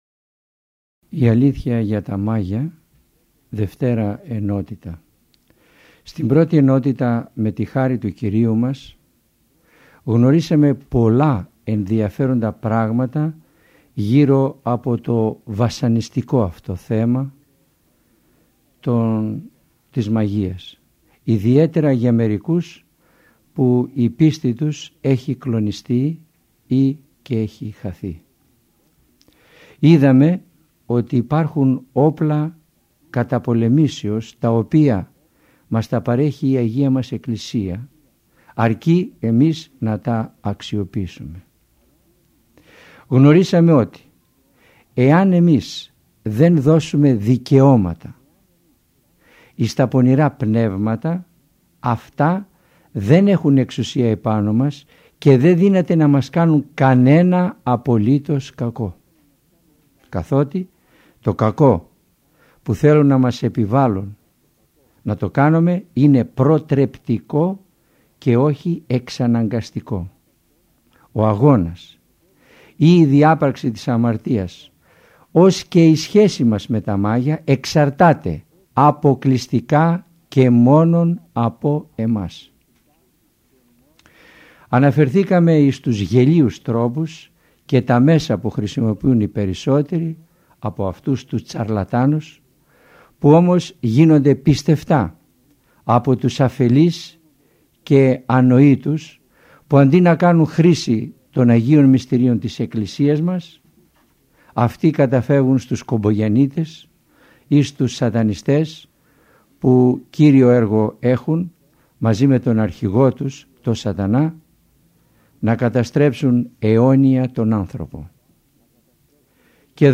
ηχογραφημένη ομιλία
Ελευθερία επιλογής Το μεγάλο πλεονέκτημα των ομιλιών Κάθε ομιλία είναι ένα ζωντανό κήρυγμα, όπου το παν εξαρτάται από τη θέληση του ακροατή˙ ο τόπος, ο χρόνος και ο τρόπος ακρόασης, το θέμα της ομιλίας εναπόκεινται στην προσωπική του επιλογή.